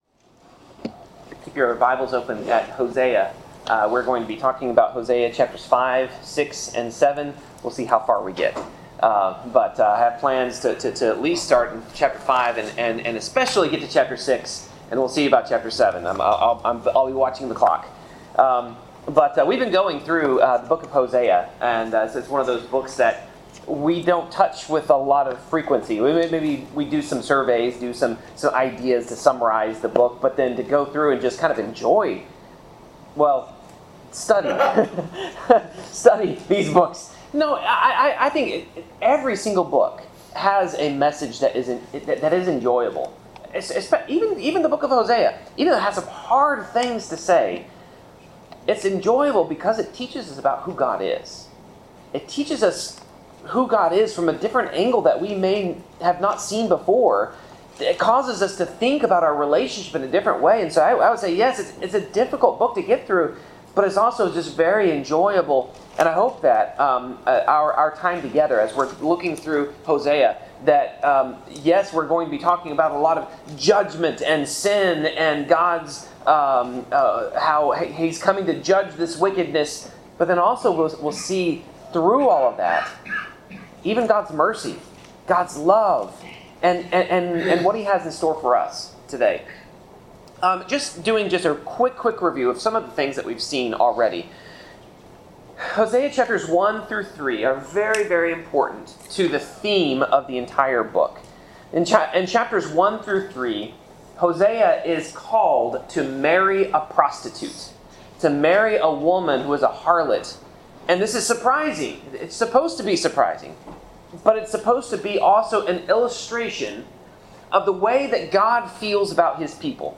Passage: Hosea 5-7 Service Type: Sermon